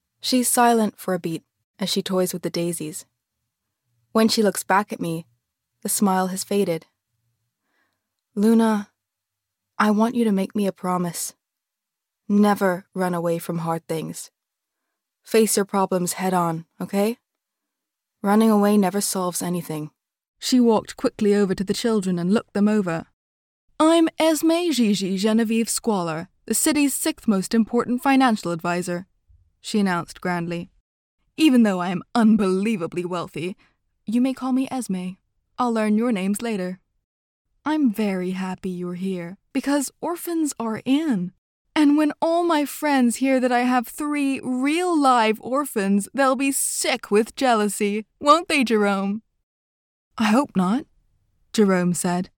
US Reel
• Home Studio
Soft and melodic
A multi-national, her voice bends effortlessly from one accent to the next, all utterly believable and with a characterful bite.